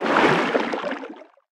Sfx_creature_snowstalker_swim_06.ogg